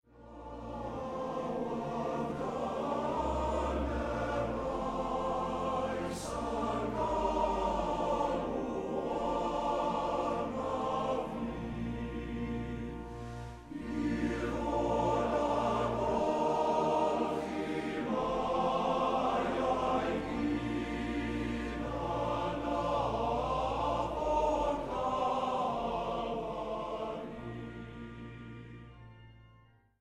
'사라'는 웨일스에서 가장 잘 알려진 찬송가 중 하나의 제목이기도 하다. 폰타르둘라이스 남성 합창단이 부르는 처음 몇 마디이다.